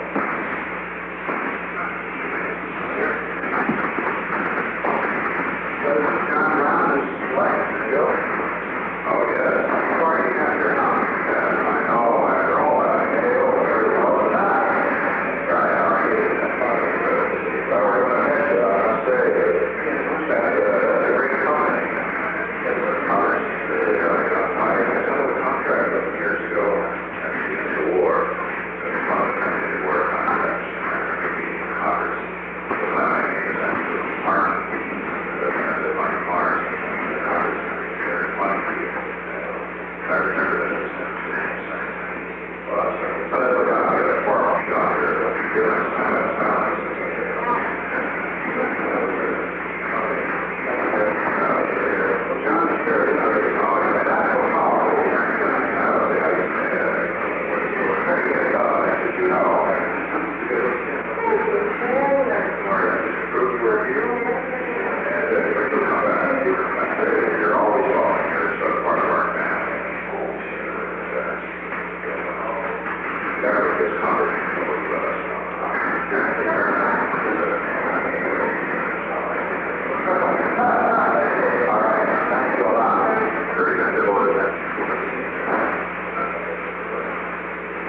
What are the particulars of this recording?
The Oval Office taping system captured this recording, which is known as Conversation 452-008 of the White House Tapes.